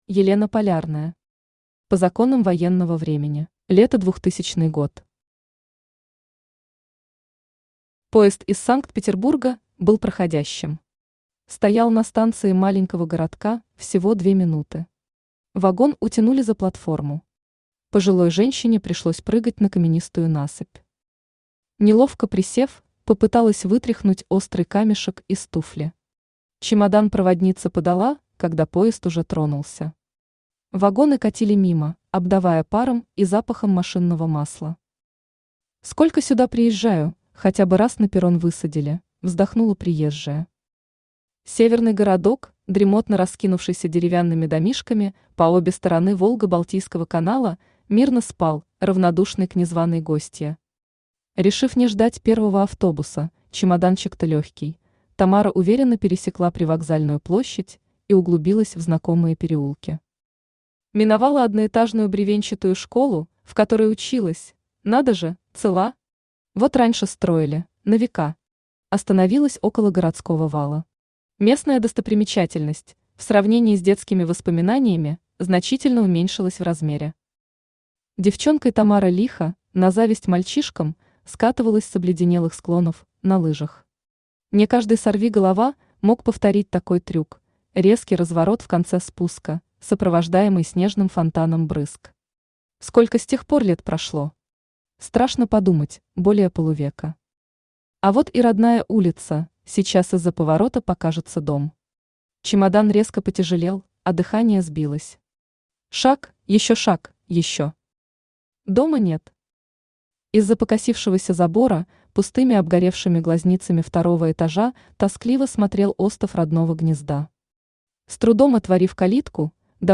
Aудиокнига По законам военного времени Автор Елена Андреевна Полярная Читает аудиокнигу Авточтец ЛитРес.